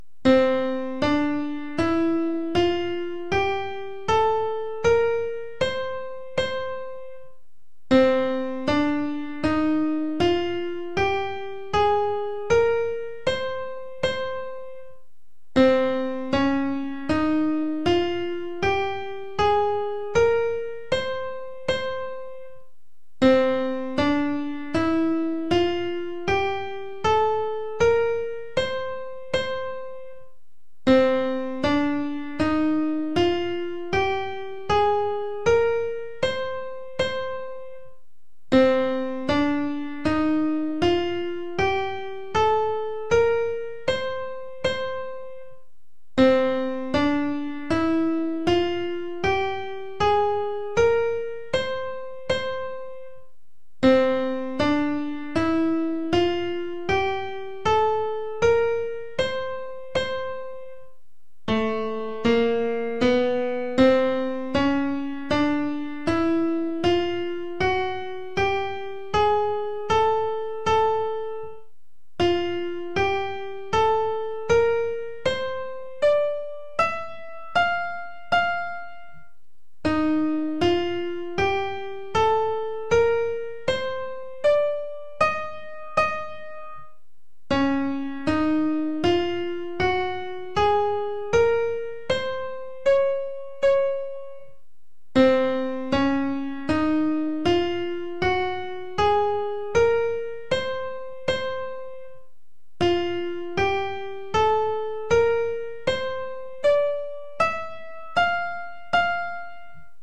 Here are some more ancient scales from various regions of the Middle East I have found in websites and books.
figure-8-9-middle-east-helmholtz.mp3